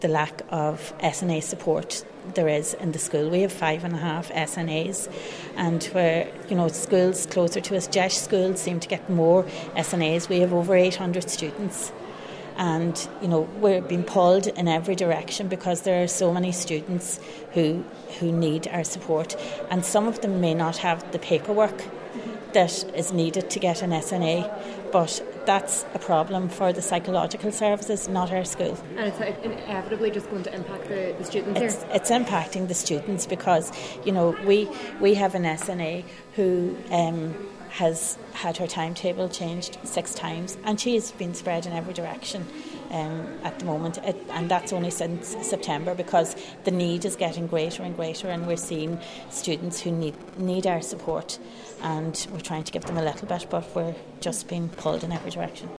A pre-election debate heard how a secondary school in Letterkenny is struggling with a lack of SNA’s.